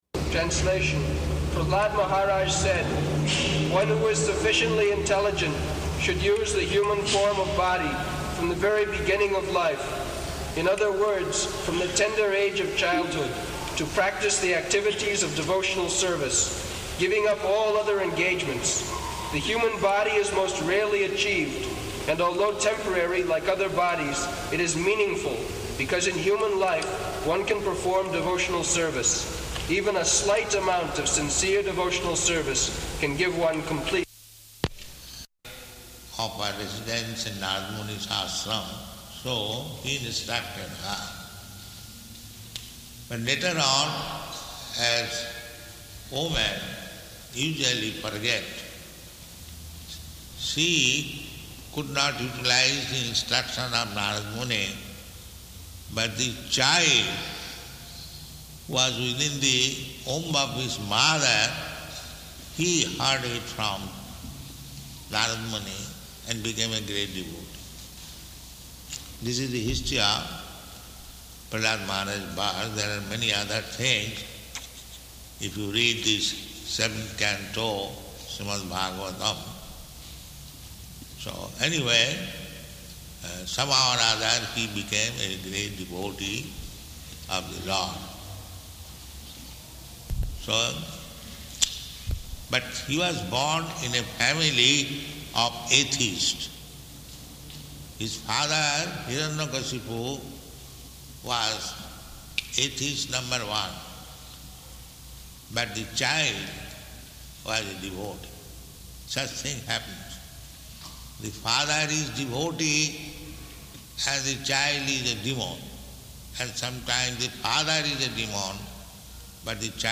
Type: Srimad-Bhagavatam
Location: Toronto